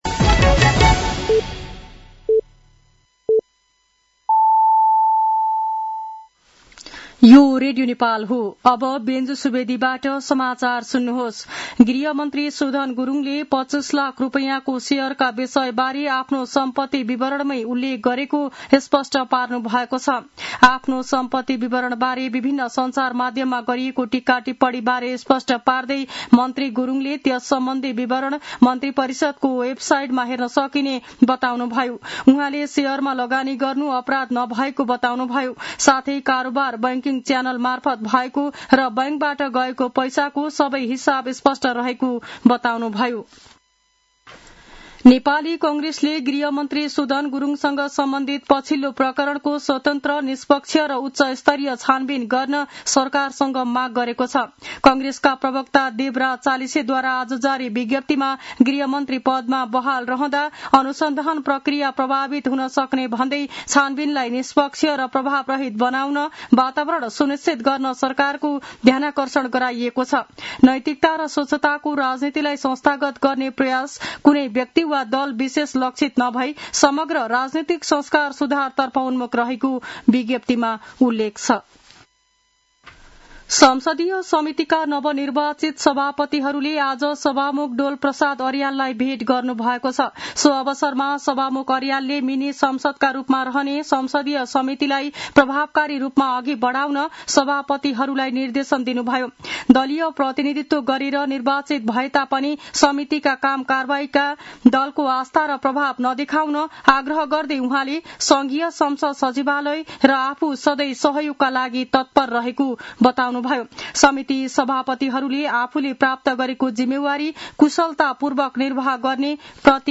An online outlet of Nepal's national radio broadcaster
साँझ ५ बजेको नेपाली समाचार : ७ वैशाख , २०८३
5-pm-nepali-news-1-07.mp3